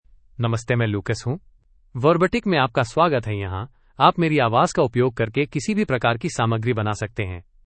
Lucas — Male Hindi (India) AI Voice | TTS, Voice Cloning & Video | Verbatik AI
Lucas is a male AI voice for Hindi (India).
Voice sample
Male
Lucas delivers clear pronunciation with authentic India Hindi intonation, making your content sound professionally produced.